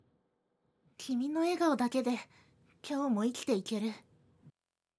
女性
ボイス